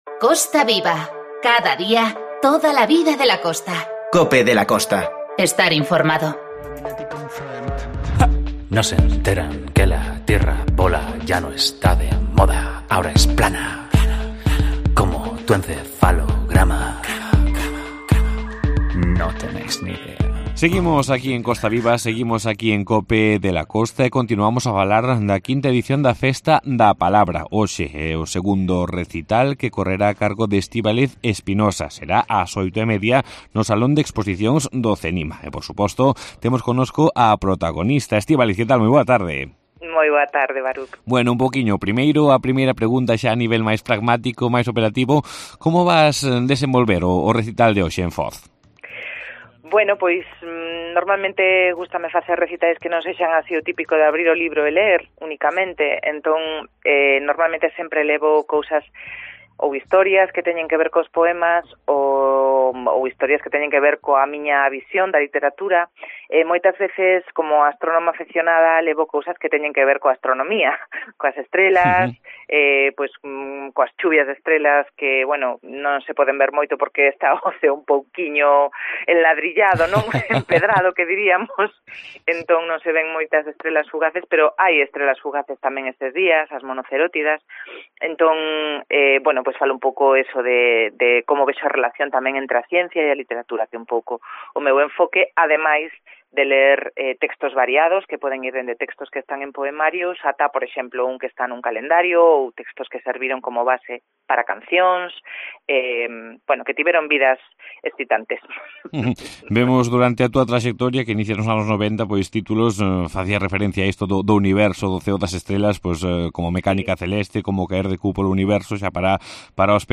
COPE de la Costa - Ribadeo - Foz Entrevista